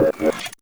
Robot Whoosh Notification 2.wav